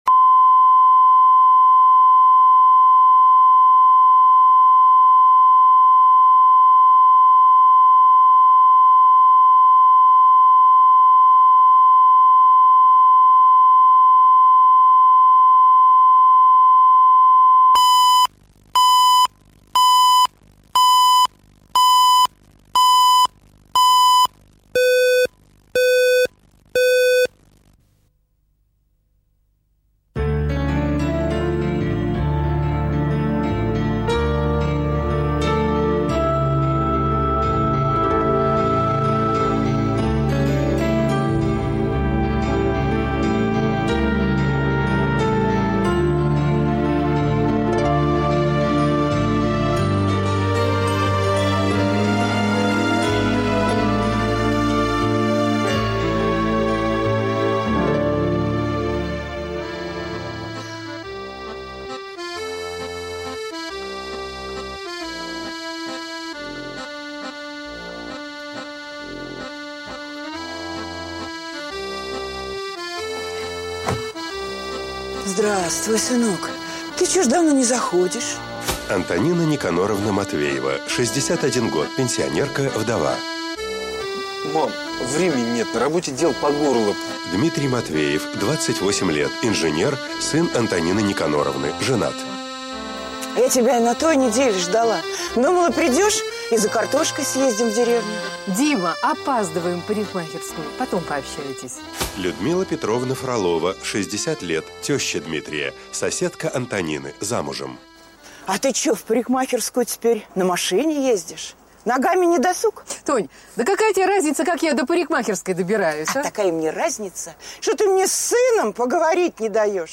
Аудиокнига Тещины блины